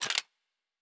Weapon 03 Foley 2.wav